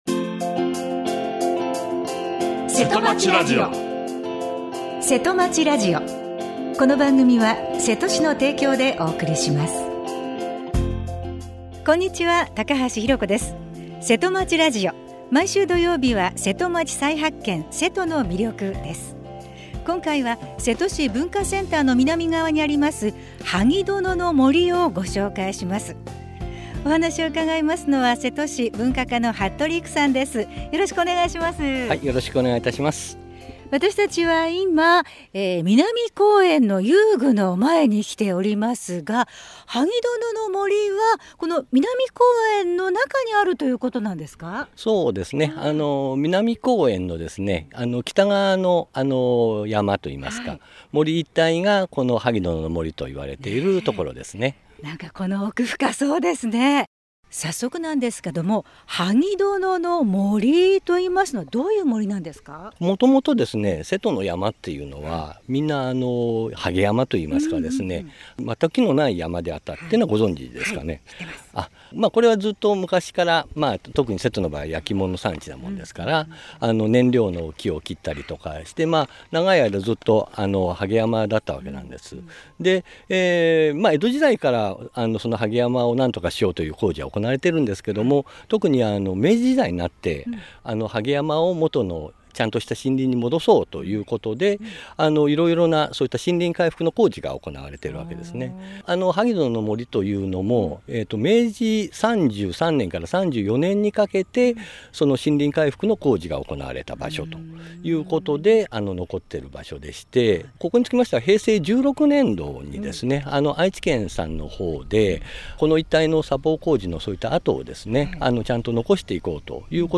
今回は、南公園の北側に広がる「萩殿の森」をご紹介します。